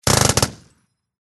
Звуки минигана
Всего несколько выстрелов из M134 Minigun калибр 7.62 мм